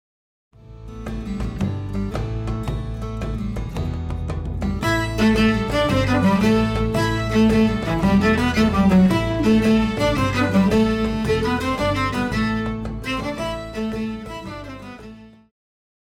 Pop
Cello
Band
Instrumental
World Music,Electronic Music
Only backing